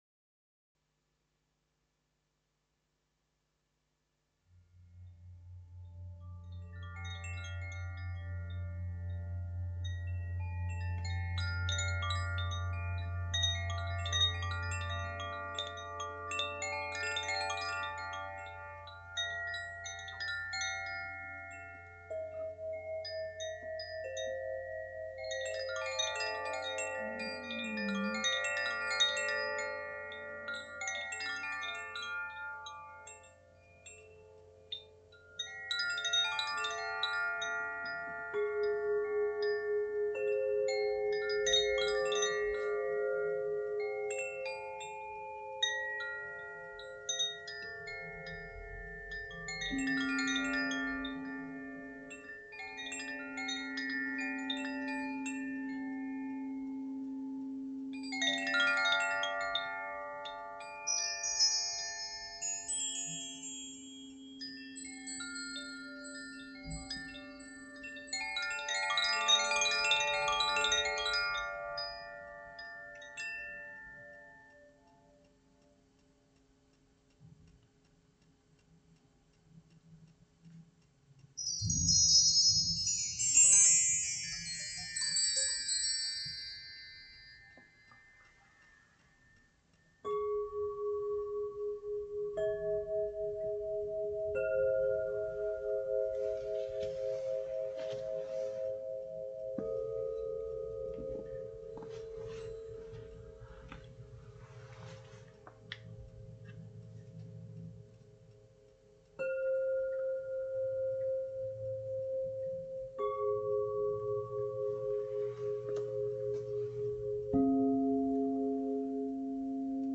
A Sound Therapy gift. 10 minutes to relax and unwind…..
creation-sound-therapy.mp3